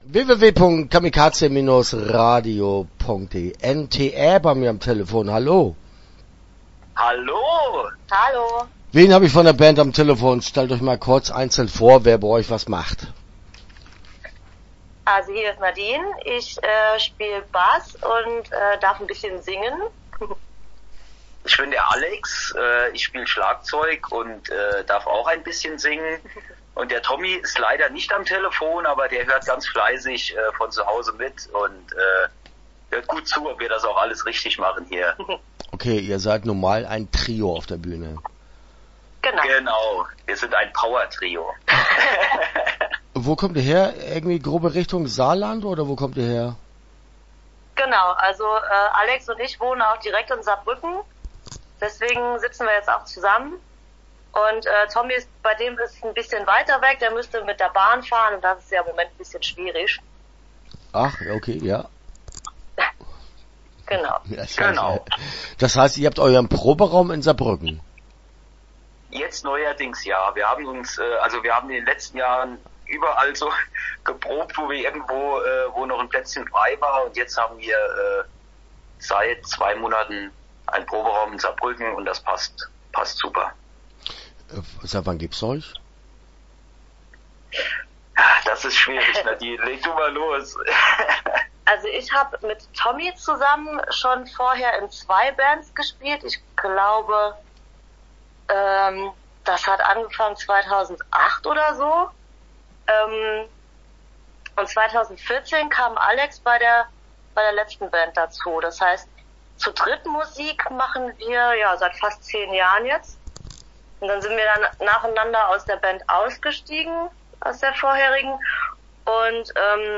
N.T.Ä. - Interview Teil 1 (10:38)